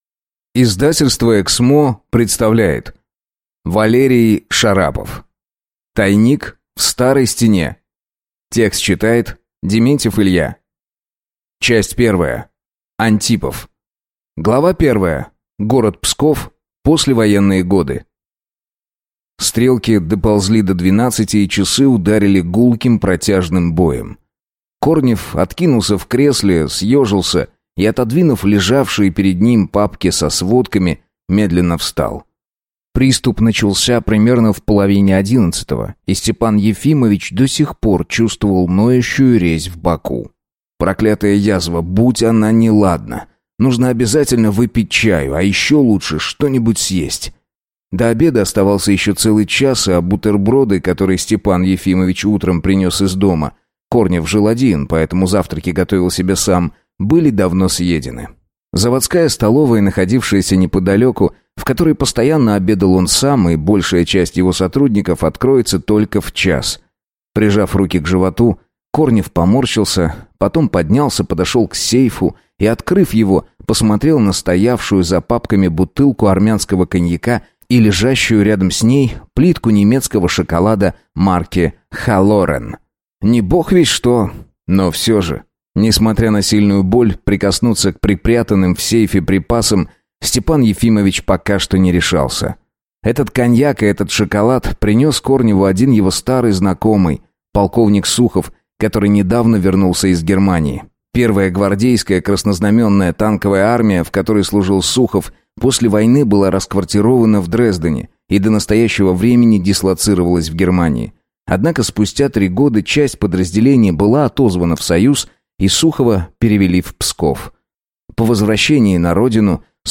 Аудиокнига Тайник в старой стене | Библиотека аудиокниг